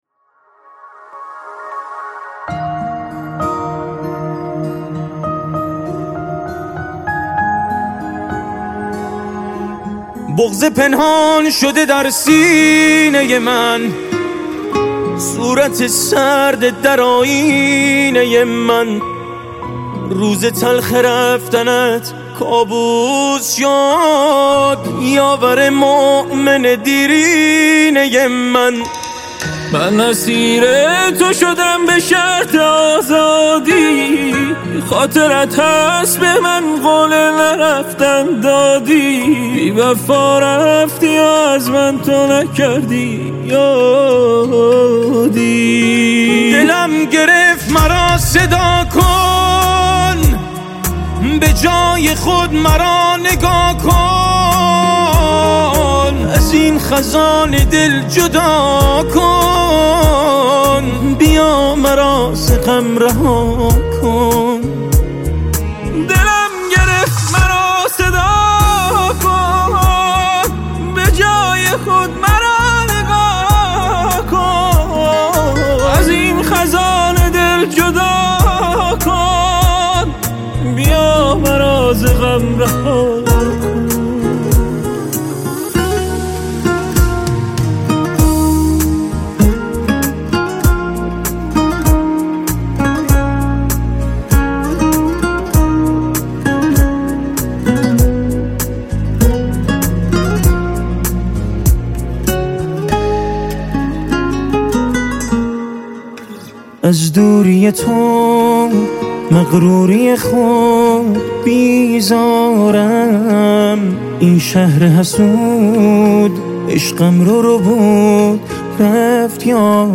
پاپ غمگین عاشقانه عاشقانه غمگین